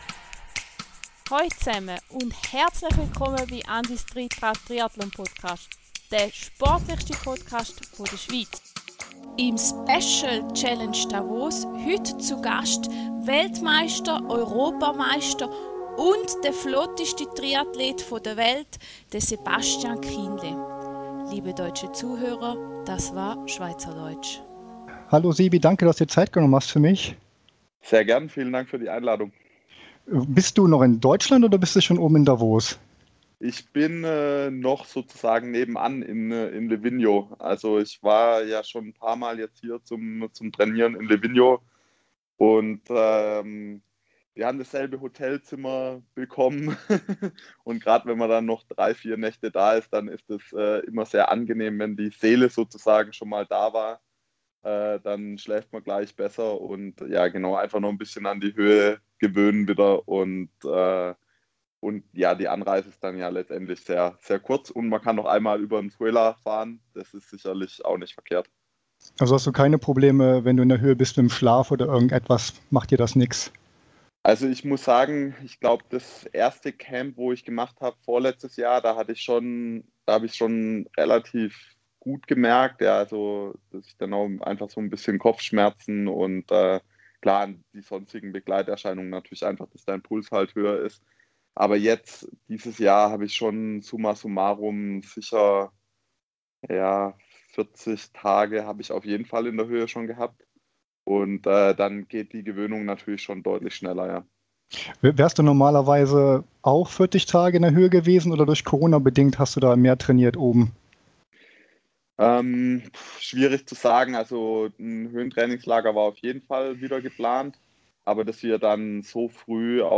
Interview_mit_Sebastian_Kienle_vor_der_Challenge_Davos.mp3